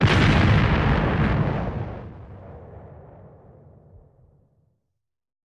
mi_explosion_03_hpx
Five different explosions.